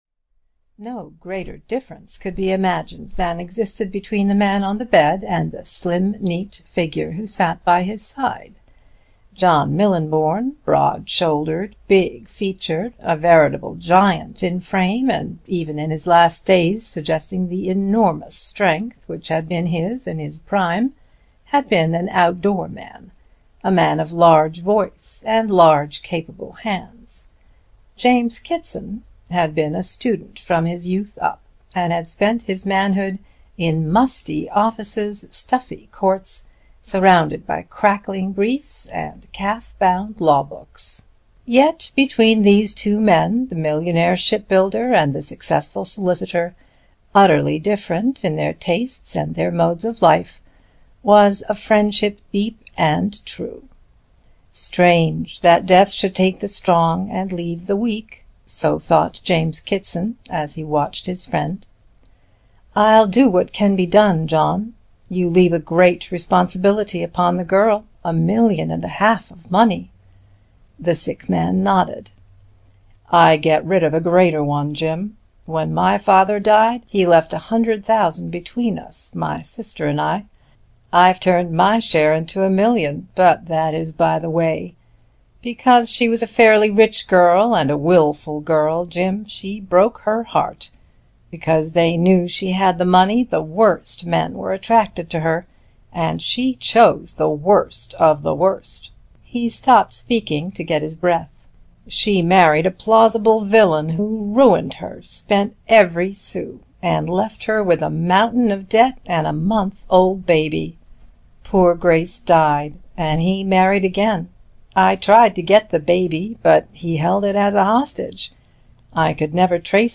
The Green Rust (EN) audiokniha
Ukázka z knihy